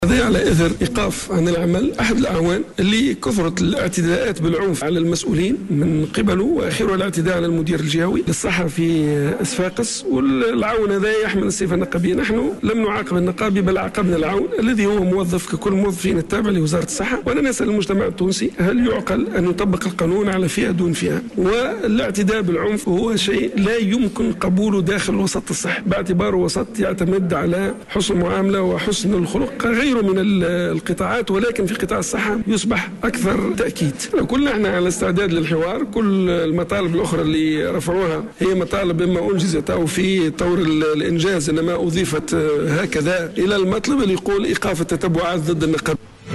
Le ministre de la Santé Abdellatif Mekki a réagit aujourd'hui lors d'un point de presse à la grève des médecins de Sfax